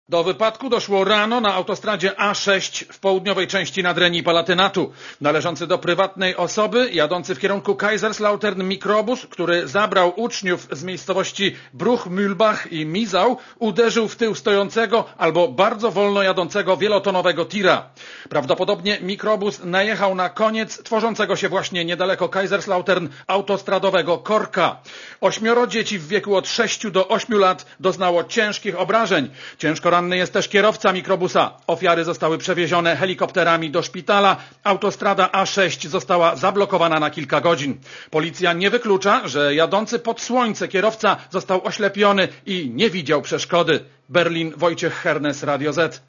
Korespondencja z Niemiec (180Kb)